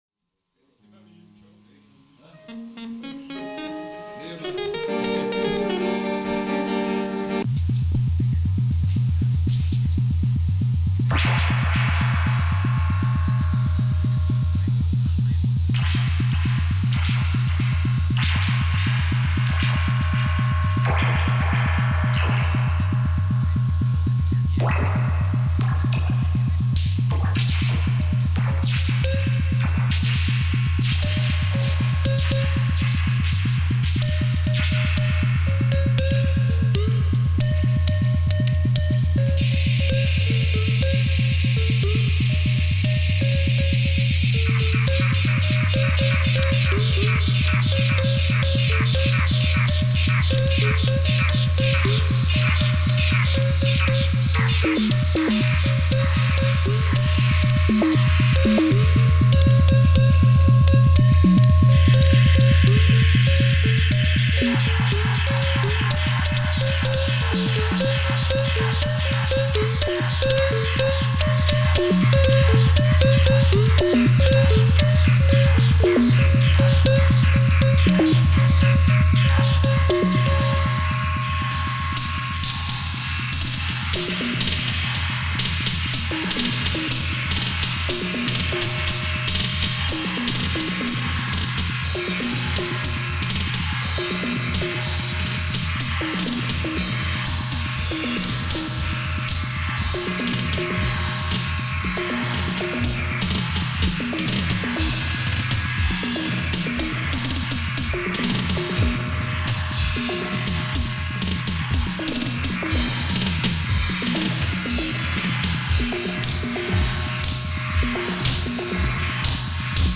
e-bass
guitar
voc, perc.
perc., sax, flute
tuba, microsynth
drums, perc.
Cut from standard cassette-material, live as recorded